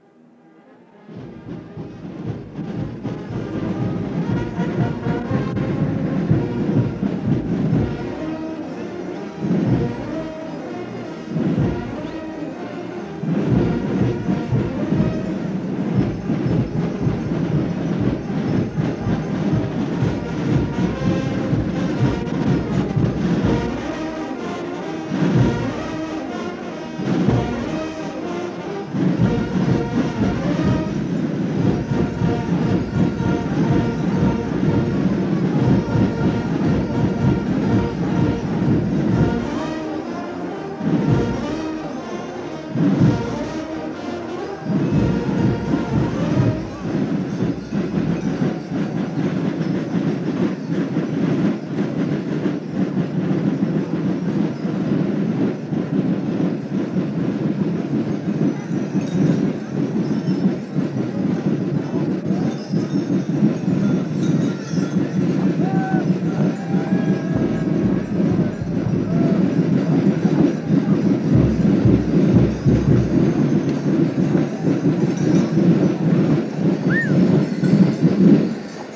Genre : instrumental
Type : musique de manifestation festive
Instrument(s) : fanfare / harmonie | tambour
Lieu d'enregistrement : Binche
Support : bande magnétique
Séquence musicale extraite d'une enquête du Musée de la Vie wallonne: Enregistrement sonore des airs traditionnels joués pendant le carnaval de Binche.